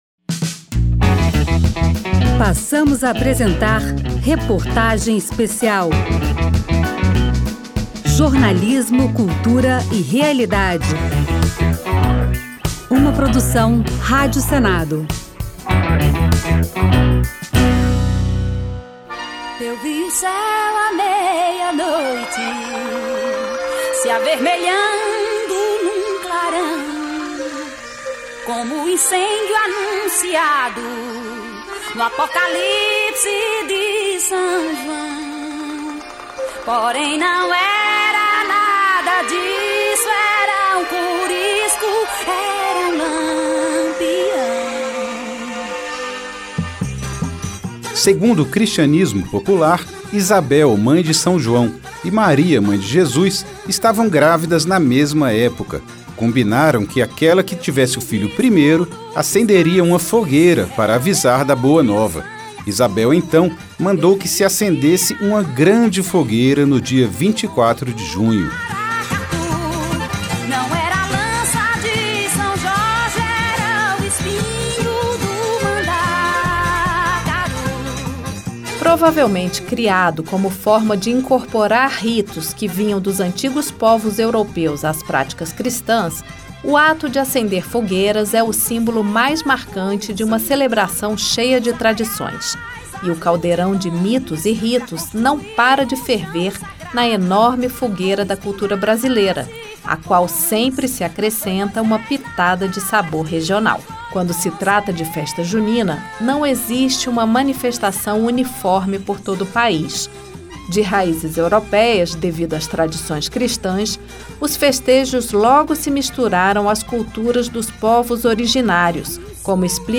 A reportagem ouviu especialistas em cultura brasileira, empresários e gente que vive as festas o ano todo.
Reportagem Especial